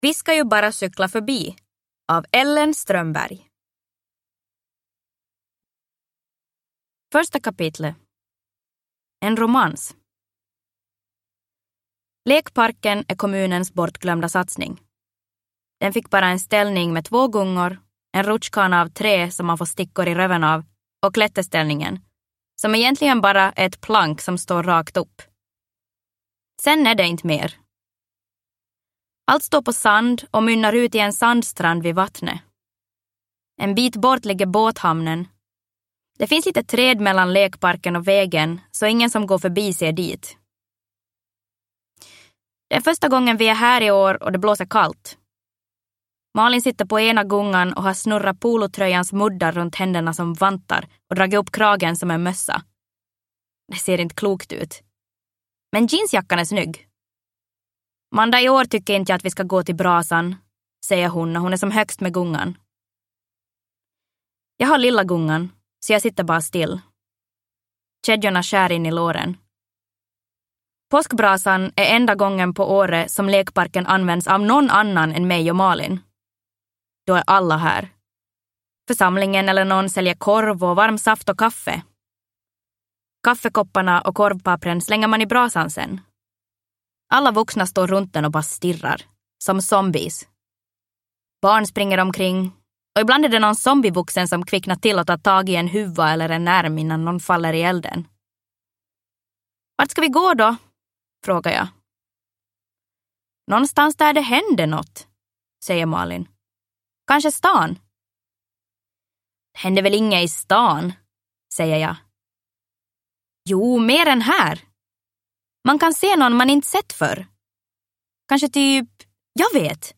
Vi ska ju bara cykla förbi – Ljudbok – Laddas ner